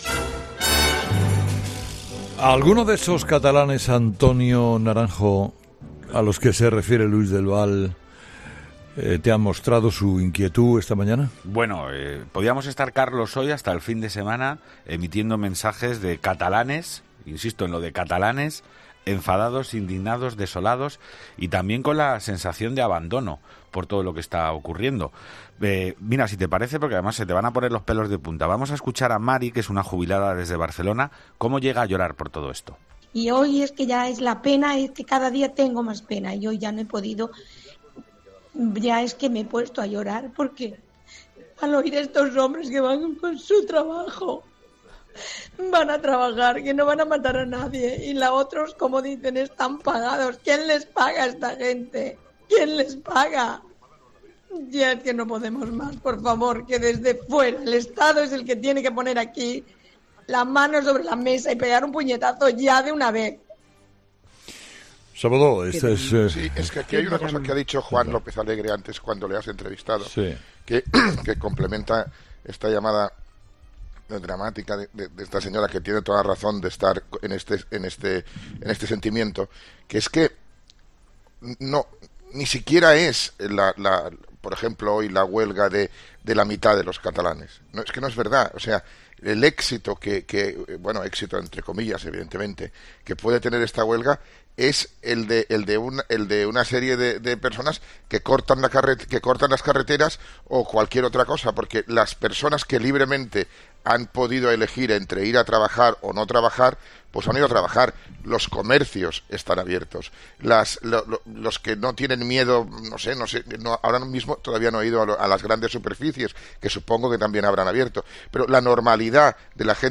Ella se refería al bloque de trabajadores que solo intentaban cumplir con sus obligaciones y no han podido. "El Estado tiene que intervenir", afirma entre sollozos. Es uno de los cientos de mensajes que hoy hemos recibido en "La Tertulia de los Oyentes", el espacio en el que la audiencia dialoga con Carlos Herrera y toda España escucha sus reflexiones.